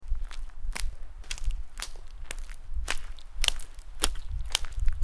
Footsteps In Puddles
Footsteps_in_puddles.mp3